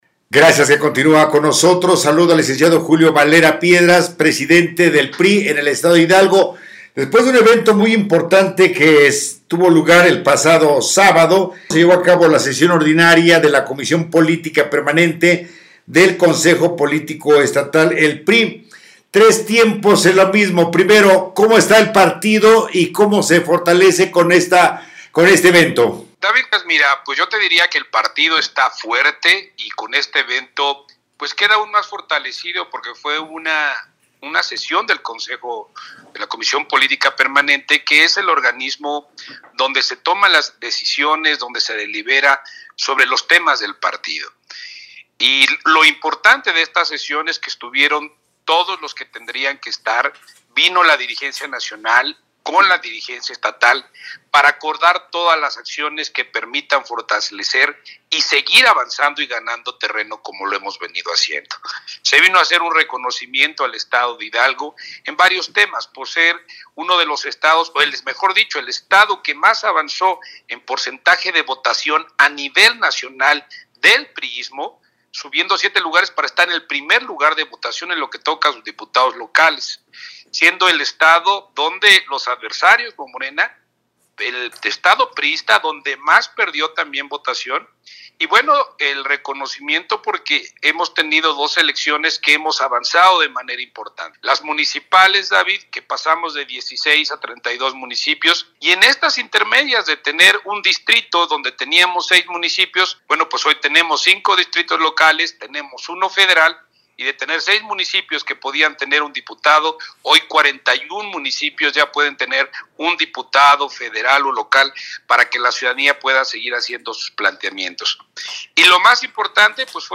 Entrevista
Entrevista-JULIO-VALERA-PIEDRAS.mp3